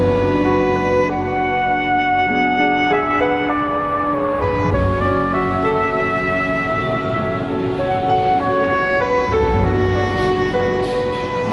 classic track need to id !!
A. Borodin - Prince Igor, Opera 1869-1887 year